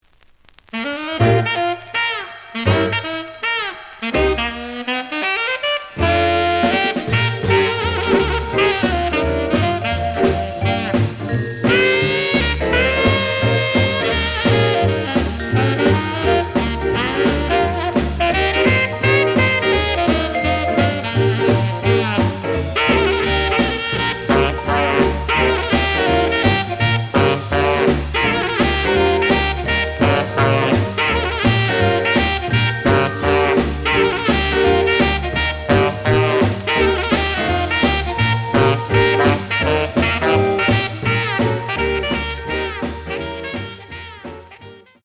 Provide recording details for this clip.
The audio sample is recorded from the actual item. Some click noise on later half of B side due to scratches.